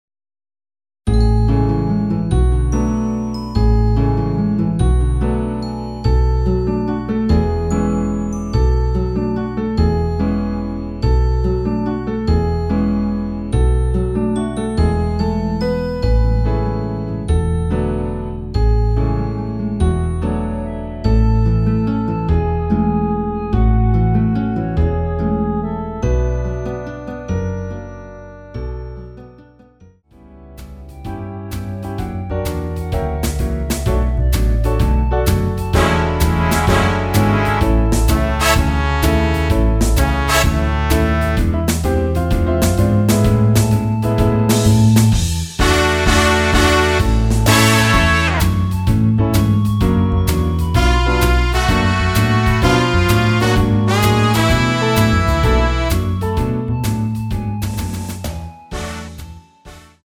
간주가 길어서 축가등에 사용하시기 좋게 간주를 4마디로 편곡 하였습니다.(미리듣기 참조)
원키에서(-2)내린 간주짧게 편곡한 멜로디 포함된? MR입니다.(미리듣기 확인)
노래방에서 노래를 부르실때 노래 부분에 가이드 멜로디가 따라 나와서
앞부분30초, 뒷부분30초씩 편집해서 올려 드리고 있습니다.
중간에 음이 끈어지고 다시 나오는 이유는